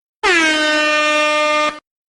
Corneta Reggaeton Sound Button - Free Download & Play